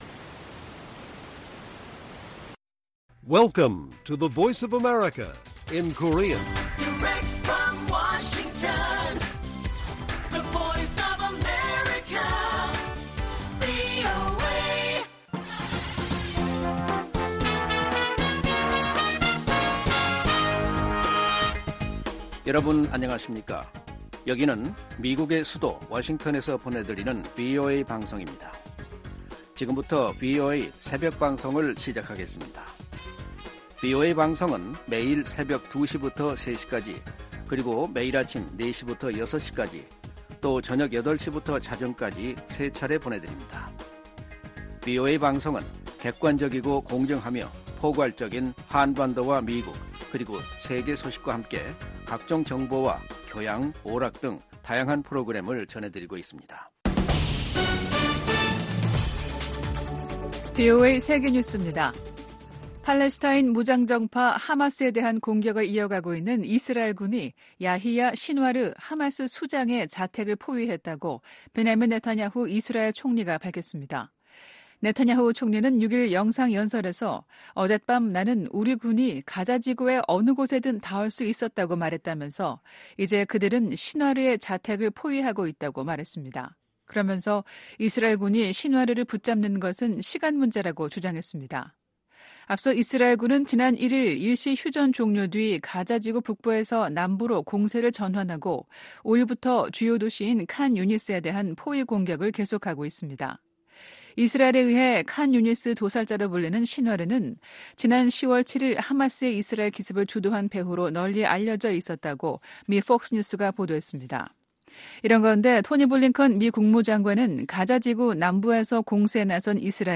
VOA 한국어 '출발 뉴스 쇼', 2023년 12월 8일 방송입니다. 한국 정부가 처음으로 김정은 북한 국무위원장의 딸 김주애 후계자 내정설에 관해 가능성 높다는 공식 판단을 내놨습니다. 미한일 안보 수장들이 오는 9일 서울에서 역내 안보 현안들을 집중 논의합니다. 자유를 향한 탈북민 가족들의 이야기를 그린 다큐 영화가 새해 초 공영방송을 통해 미국의 안방에 방영됩니다.